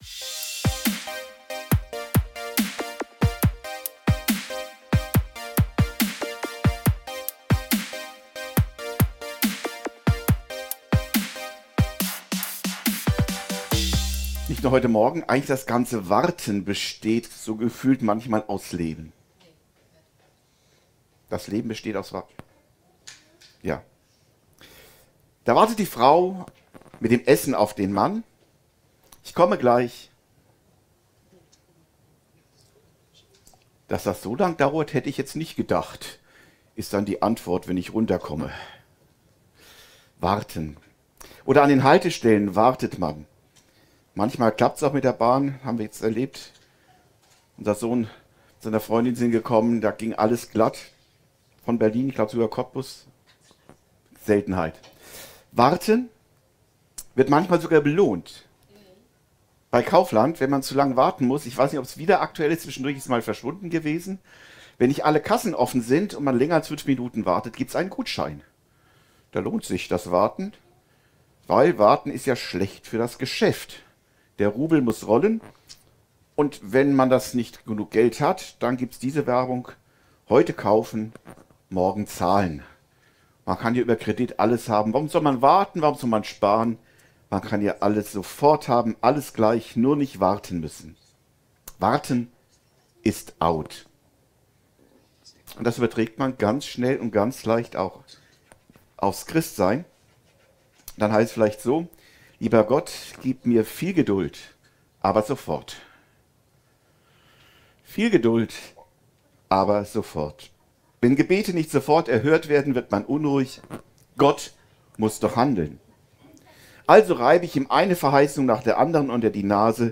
Warten lohnt sich! ~ Predigten u. Andachten (Live und Studioaufnahmen ERF) Podcast